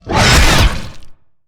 Sfx_creature_squidshark_chase_os_03.ogg